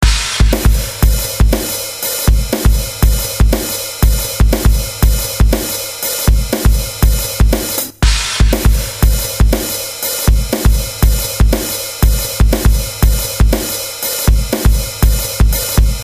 欣欣向荣原声大鼓11
描述：我在原声鼓的基础上叠加了一个合成的低音鼓，使它更有冲击力。
Tag: 120 bpm Hip Hop Loops Drum Loops 2.70 MB wav Key : Unknown